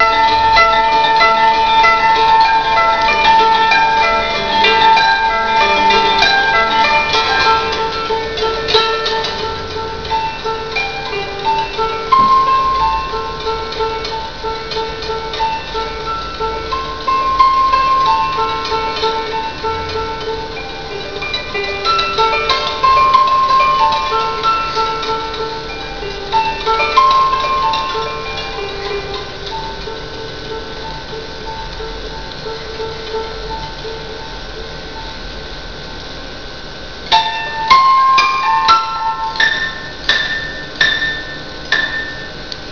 for koto and voice